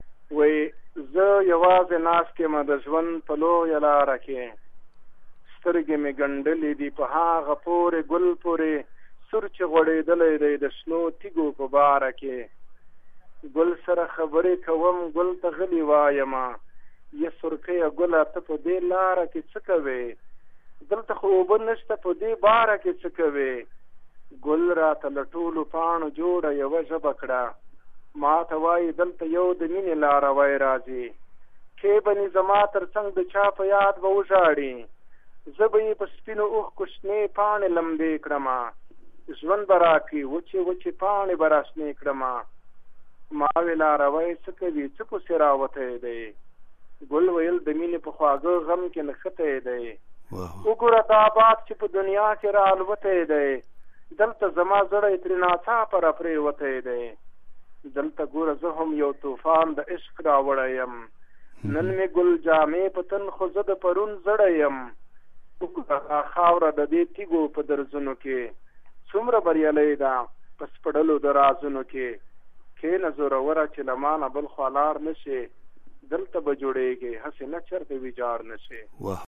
د کاروان په شعر کې د ټولنې دردونه، خوښۍ، ښادۍ او رنځونه انځور شوي دي. دا چې کاروان پخپله خپل کوم شعر ډیر خوښوي، راځئ چې هغه دده په خپل غږ واوروو:
د پیرمحمد کاروان یو شعر دده په خپل غږ کې